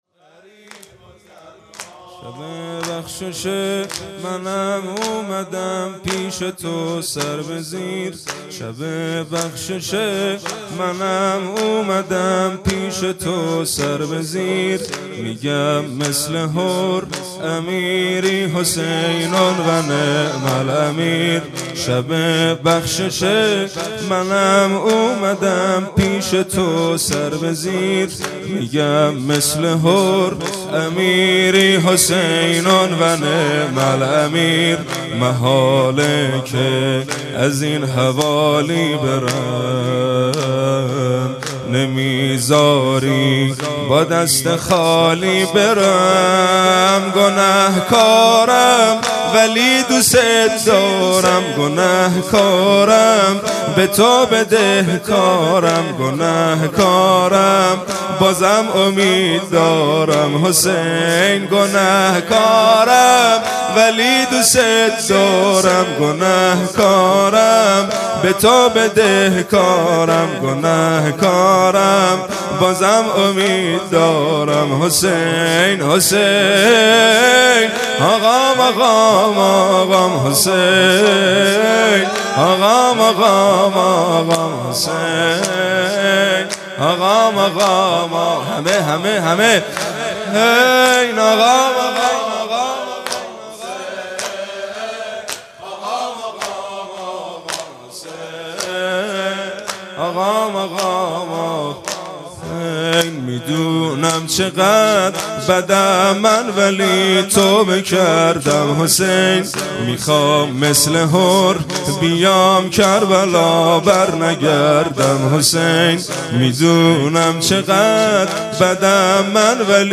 شب هفتم ماه مبارک رمضان
? زمینه: